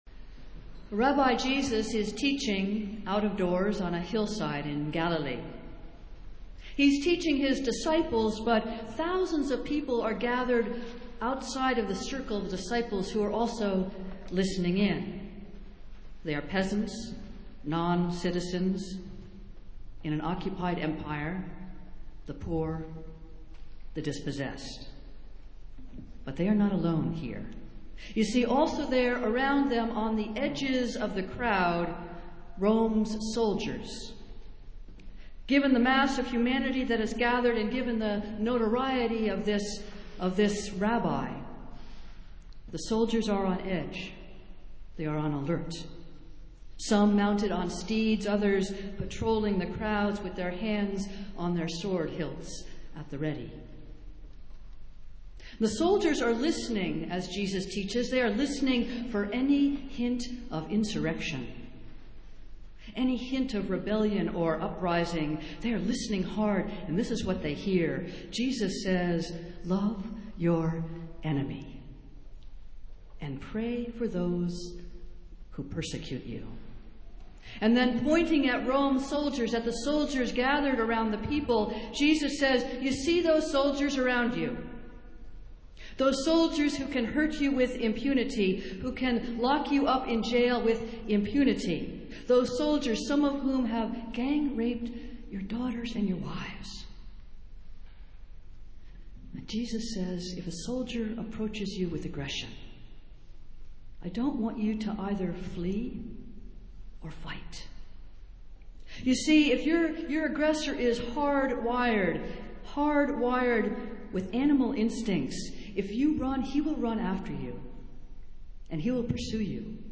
Festival Worship - Fifth Sunday in Lent